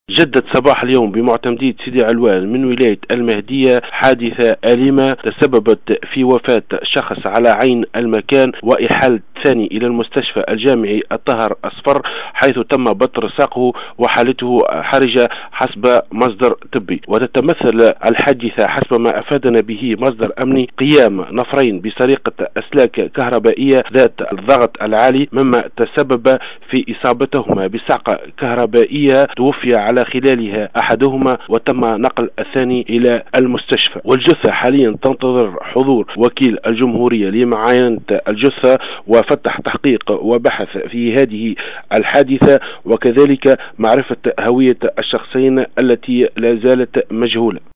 أكثر تفاصيل مع مراسلنا بالمهدية